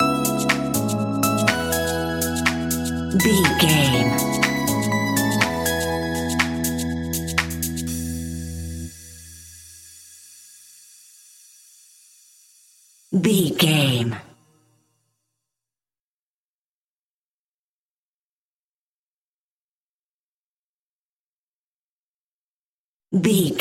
Ionian/Major
groovy
uplifting
futuristic
energetic
repetitive
synthesiser
electric piano
drum machine
house
electro house
funky house
synth leads
synth bass